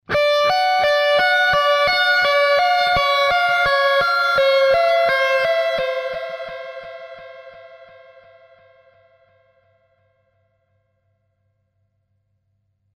Effetti speciali
Ambulanza.mp3